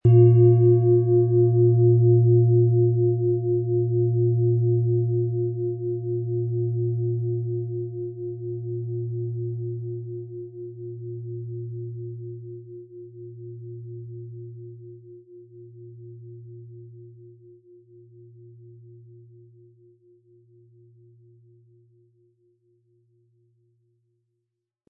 Von Hand hergestellte Klangschale mit dem Planetenton Wasser.
• Tiefster Ton: Mond
Im Sound-Player - Jetzt reinhören hören Sie den Original-Ton dieser Schale.
PlanetentöneWasser & Mond
MaterialBronze